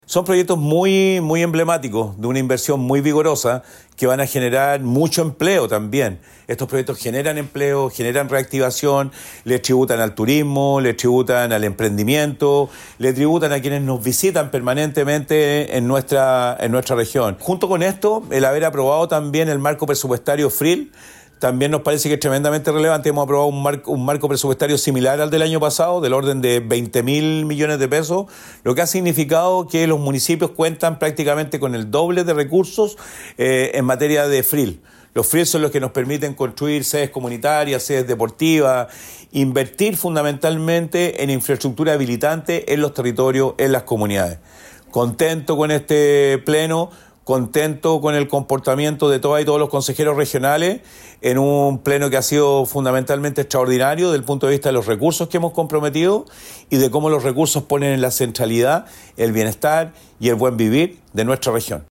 El Gobernador Regional de Valparaíso, Rodrigo Mundaca, dijo lo siguiente.